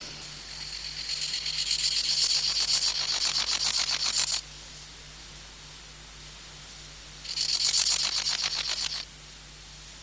Акустические сигналы: самц с одной задней левой ногой возле самки, Россия, 19 м ЮВ Владивостока, осоково-злаковые заросли на берегу моря, 25.VIII 1995; запись